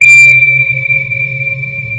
sonarPingWaterMedium3.ogg